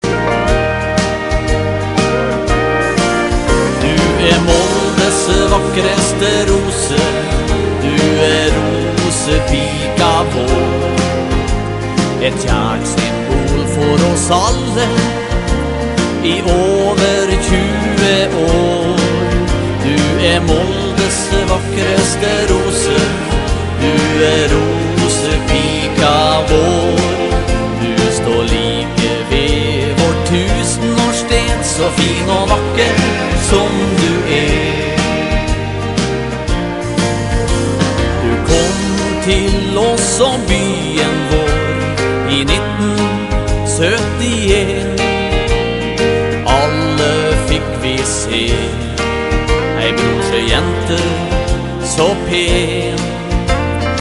trekkspill, tangenter, gitar, sang, kor
trommer, sang, kor